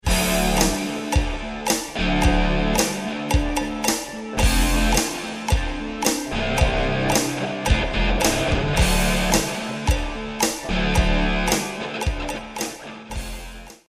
intense category-defying instrumentals